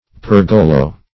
Search Result for " pergolo" : The Collaborative International Dictionary of English v.0.48: Pergolo \Per"go*lo\, n. [It.] A continuous colonnade or arcade; -- applied to the decorative groups of windows, as in Venetian palazzi.